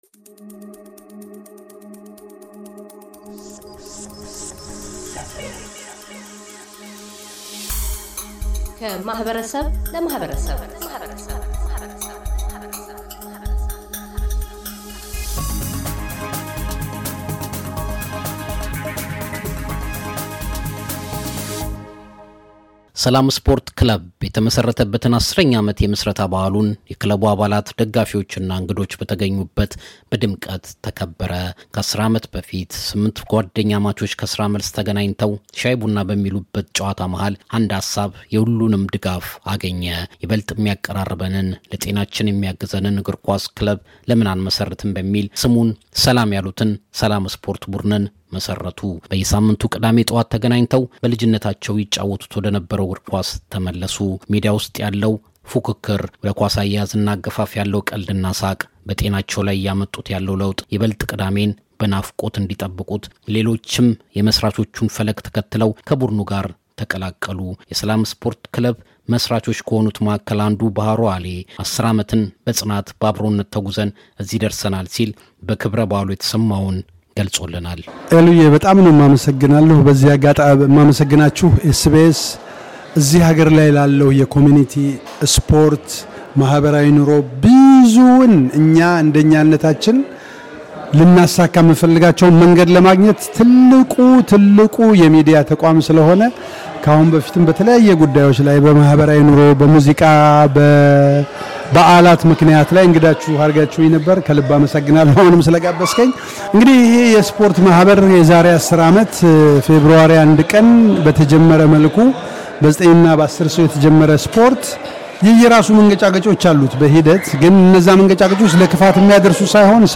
የሰላም ስፖርትና ማኅበራዊ ክለብ 10ኛ የምሥረታ ዓመቱን ማክበርን አስመልክቶ፤ የክለቡ መሥራቾች፣ አመራር አባላትና ታዳሚዎች አተያያቸውን ያጋራሉ።